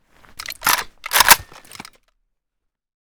ak74m_unjam.ogg